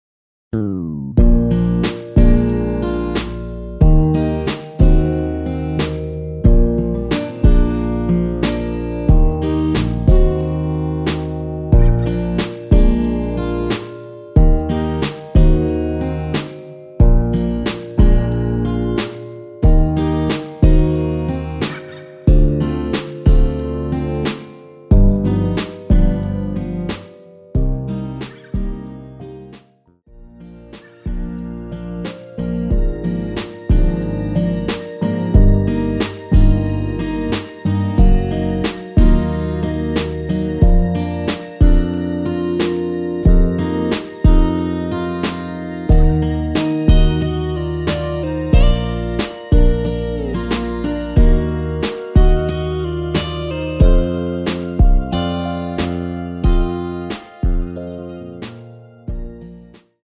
대부분의 여성분이 부르실수 있도록 제작한 키의 MR 입니다.
Bb
앞부분30초, 뒷부분30초씩 편집해서 올려 드리고 있습니다.